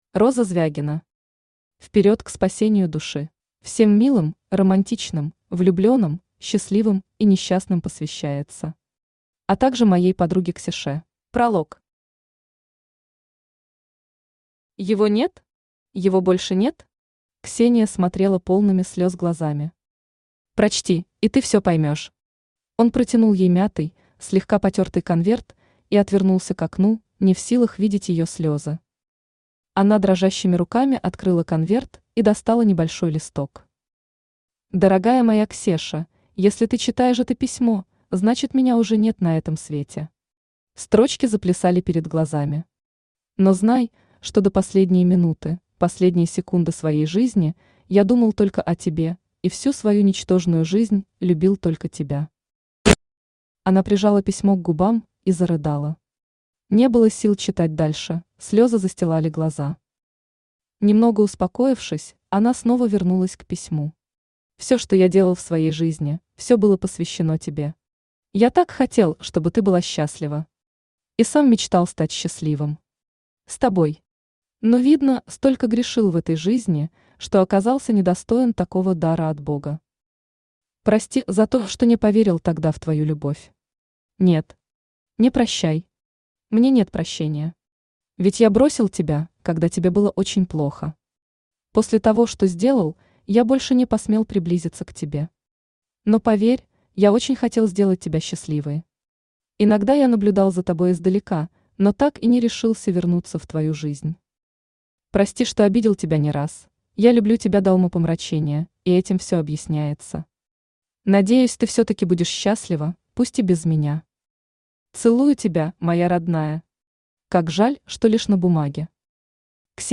Аудиокнига Вперед к спасению души | Библиотека аудиокниг
Aудиокнига Вперед к спасению души Автор Юлия Звягина Читает аудиокнигу Авточтец ЛитРес.